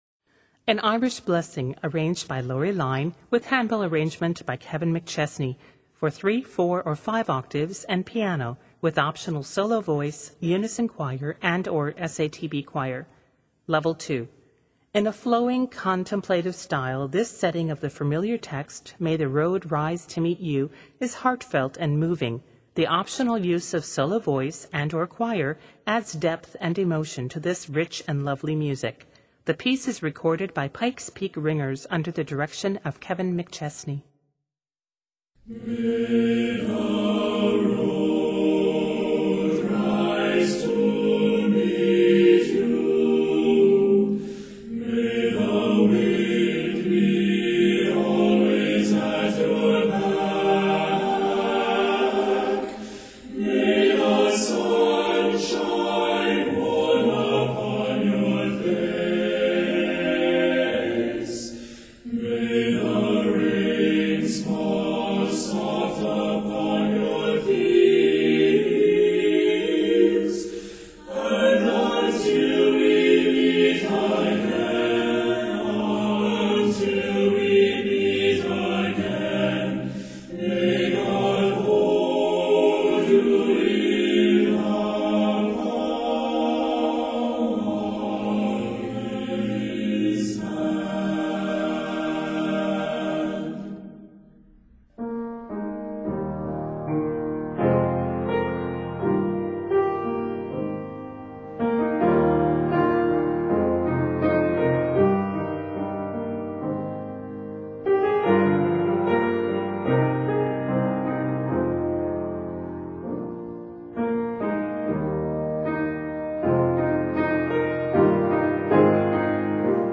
In a flowing, contemplative style